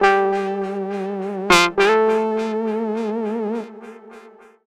VOS SYNT 2-L.wav